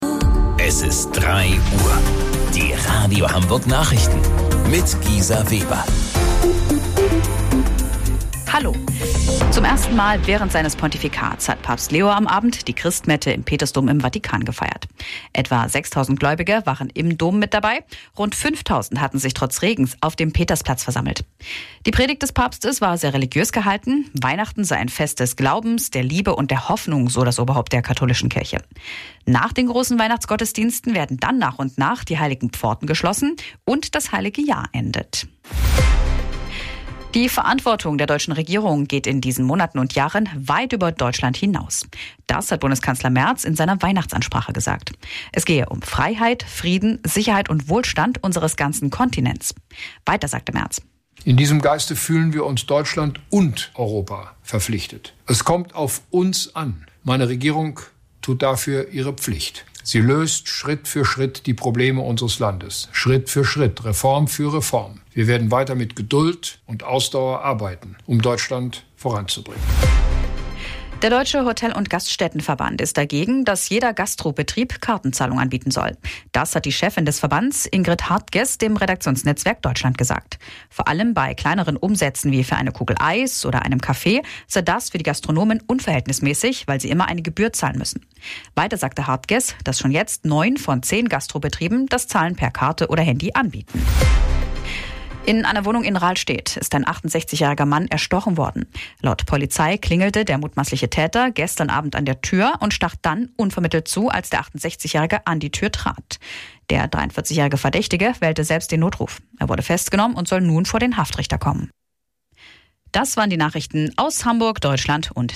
Radio Hamburg Nachrichten vom 25.12.2025 um 03 Uhr